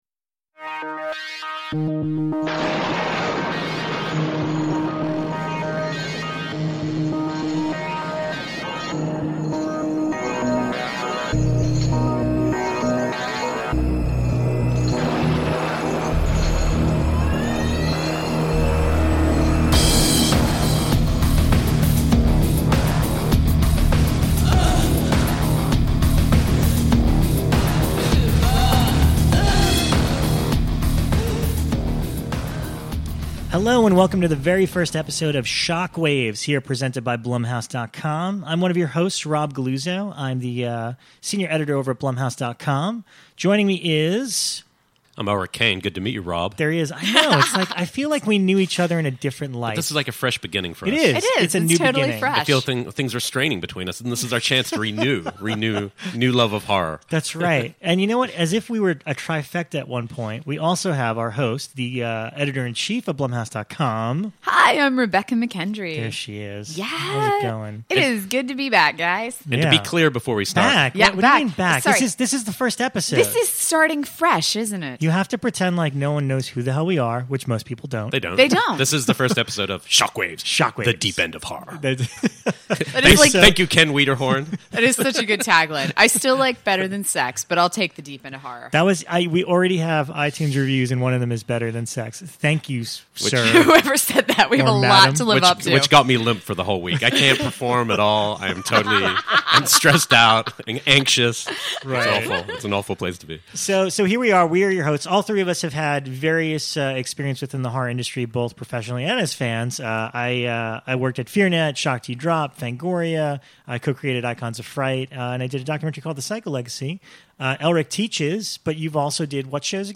The trio talk about their all time favorite horror movies, first time they recognized a genre actor in a non-g...